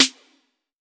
Urban Snare 03.wav